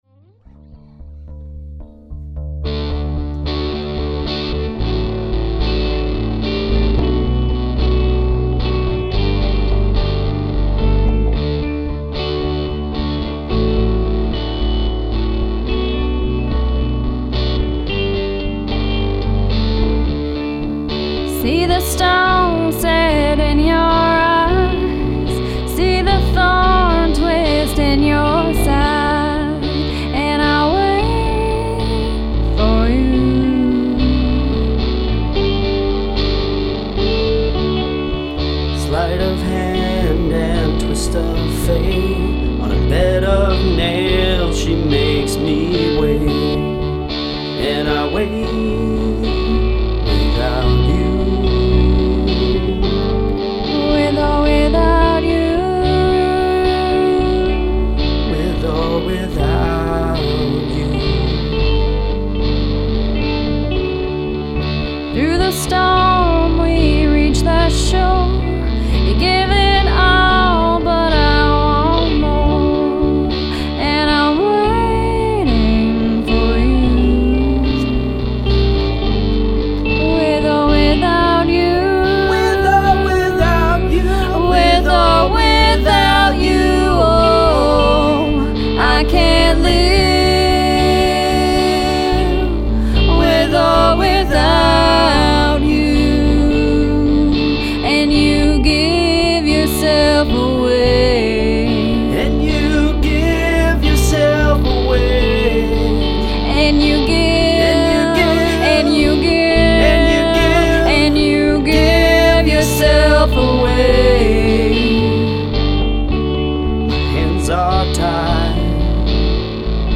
It is rough.